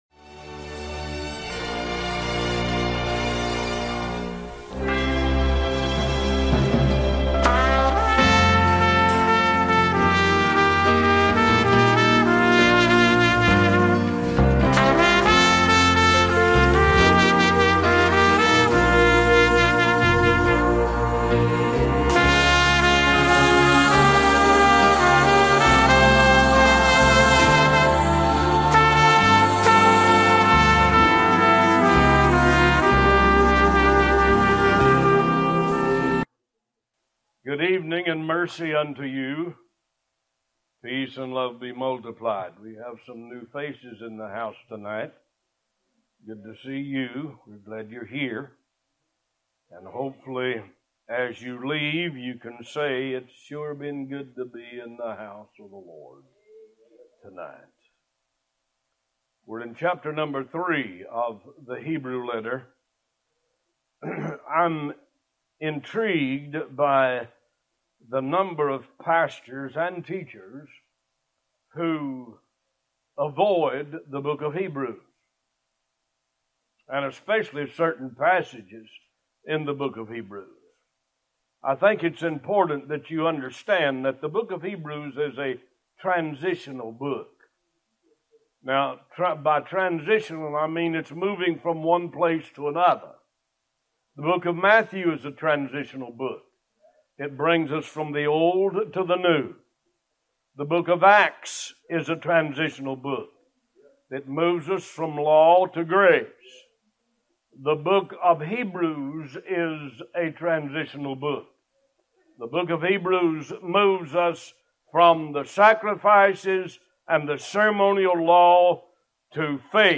Talk Show Episode, Audio Podcast, One Voice and Jesus Is Better on , show guests , about Jesus Is Better, categorized as History,Philosophy,Religion,Christianity,Society and Culture